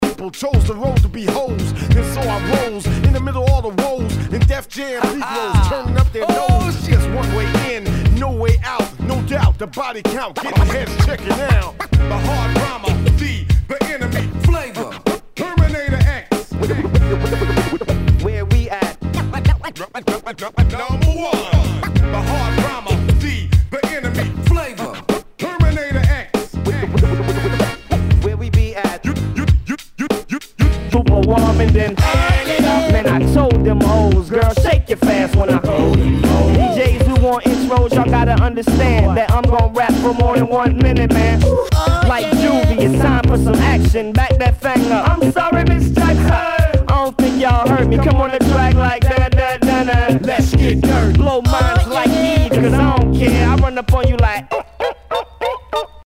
HIPHOP/R&B
ナイス！ヒップホップ！